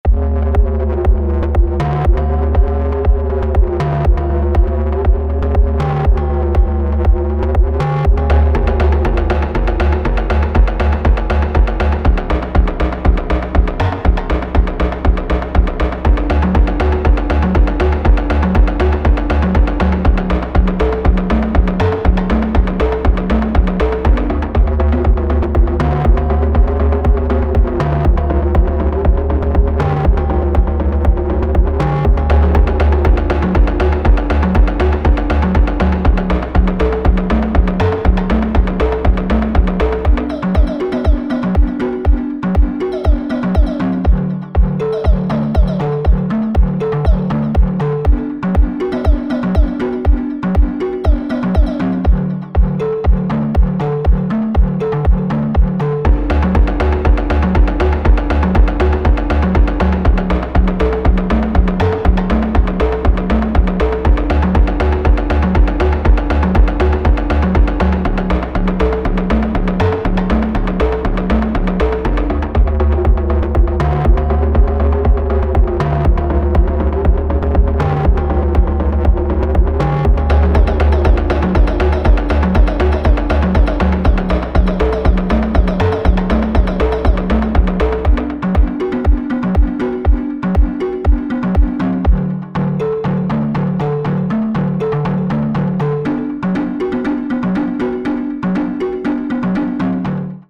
1 pattern, messing with mutes.
pseudo side-chain on the FX track for kicks.
Both the bass and drone sounds are basically doubled.
Then the two melodic lines harmonizing, doing close to the same as doubling.